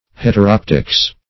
Heteroptics \Het`er*op"tics\